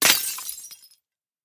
glassSmash.ogg